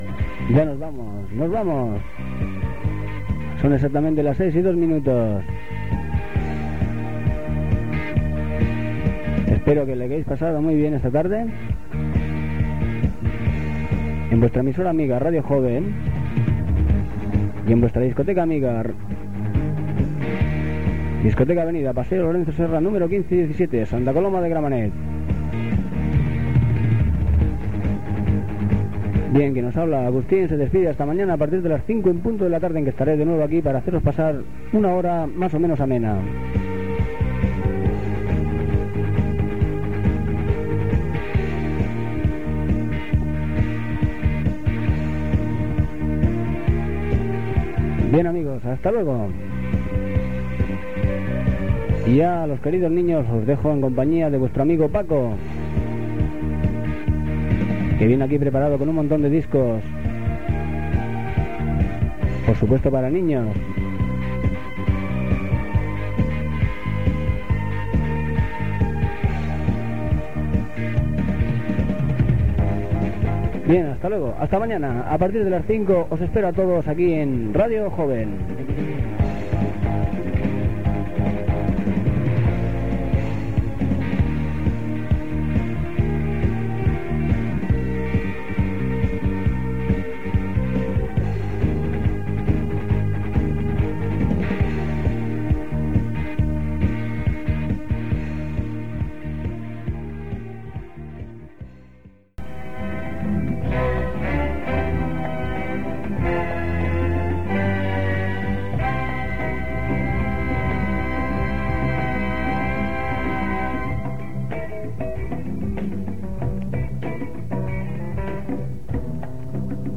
Final programa musical, programació de l'emissora i inici de "Chiquilandia".
Infantil-juvenil
FM